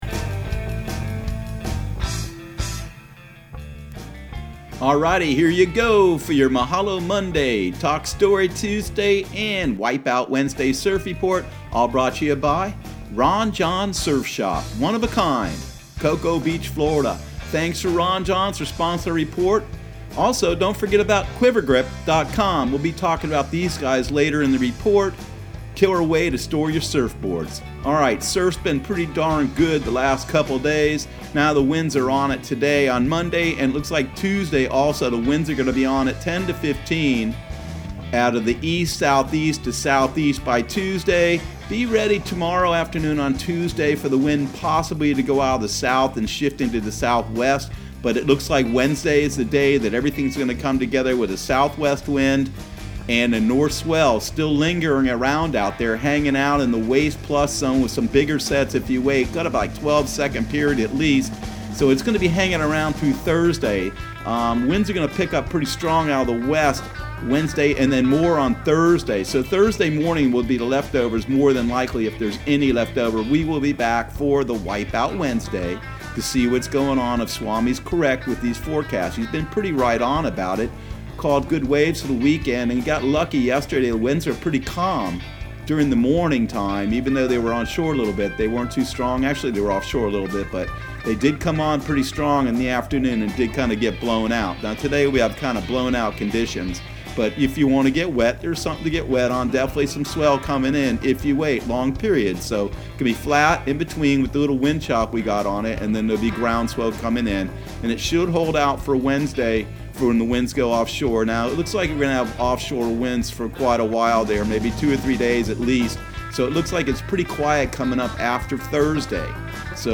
Surf Guru Surf Report and Forecast 04/06/2020 Audio surf report and surf forecast on April 06 for Central Florida and the Southeast.